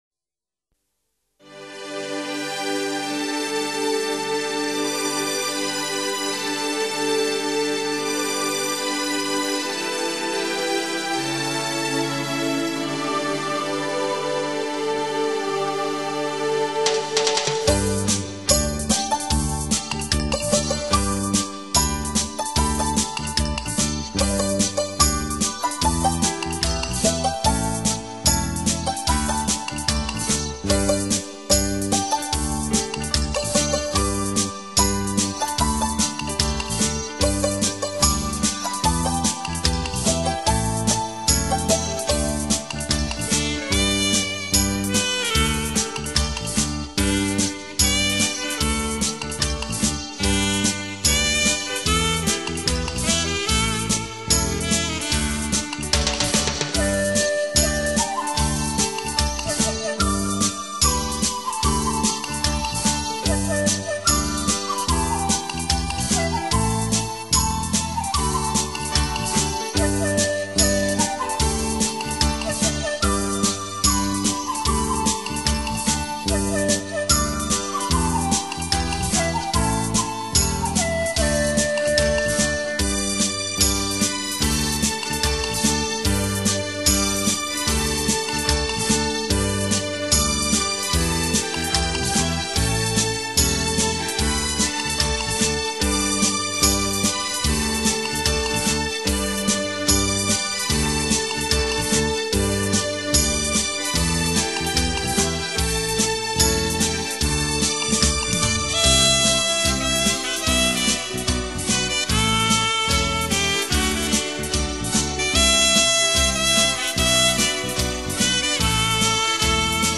把温情无限的音乐改编成热情奔放的交谊舞曲，